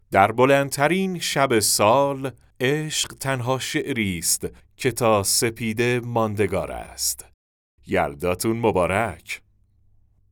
نریشن شب یلدا